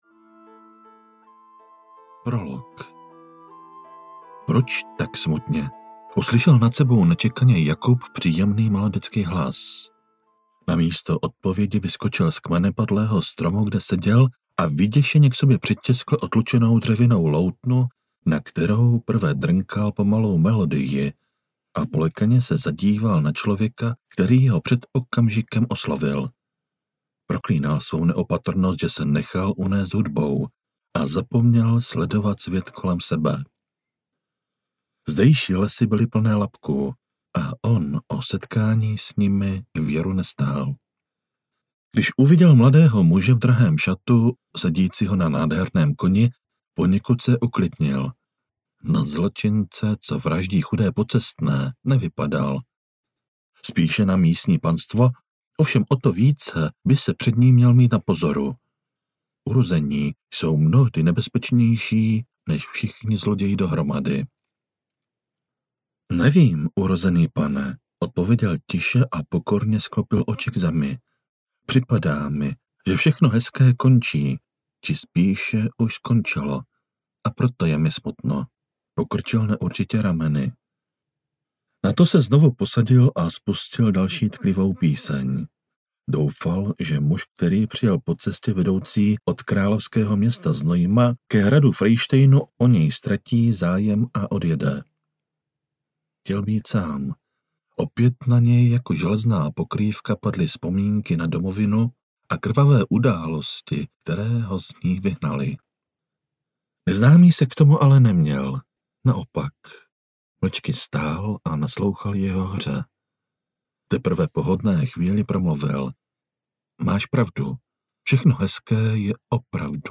Ďábel z Frejštejna audiokniha
Ukázka z knihy
dabel-z-frejstejna-audiokniha